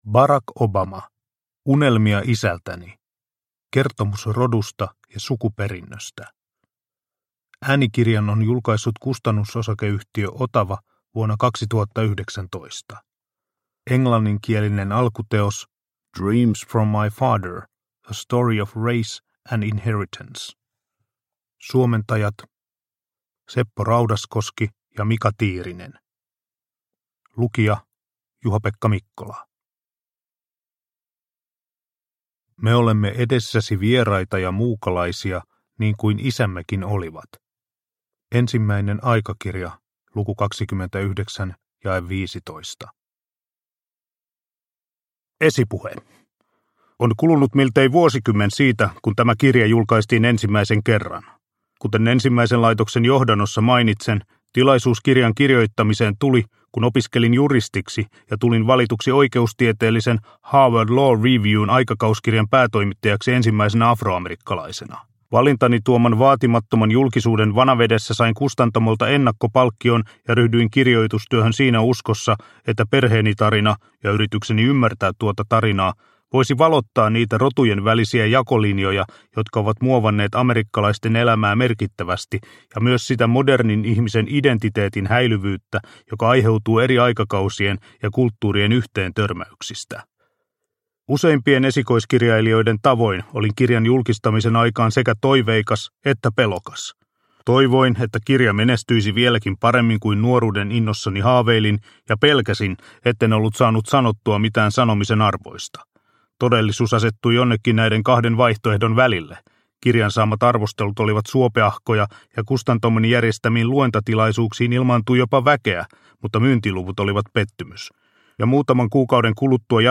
Unelmia isältäni – Ljudbok – Laddas ner